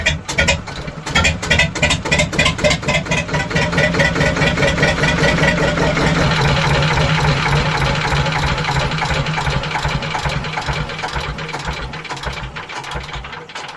verschuiven boot.mp3